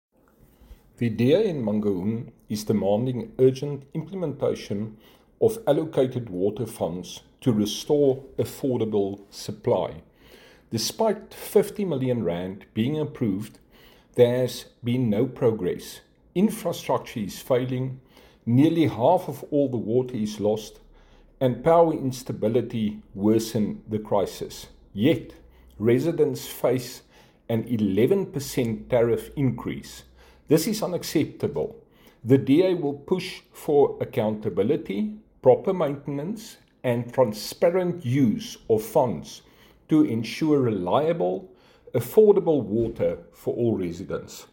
Afrikaans soundbites by Cllr Hardie Viviers and